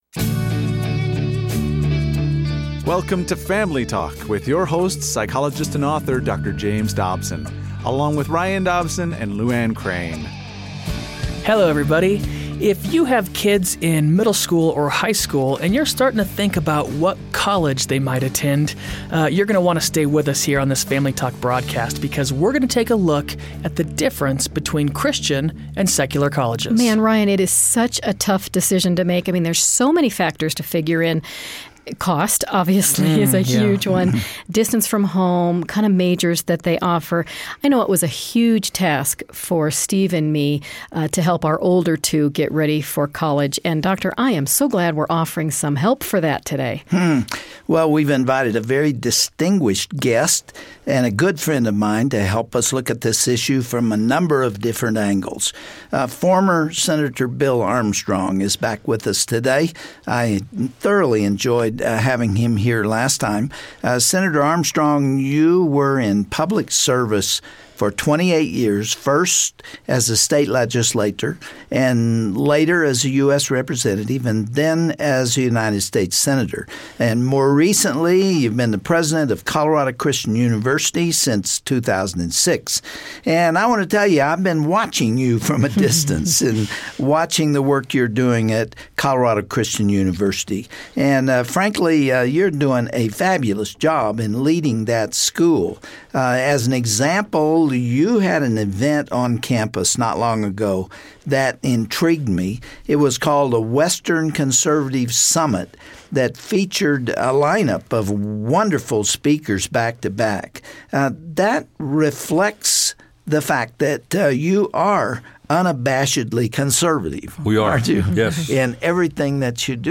Host Dr. James Dobson
Guest(s):Senator Bill Armstrong